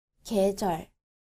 • gyejeol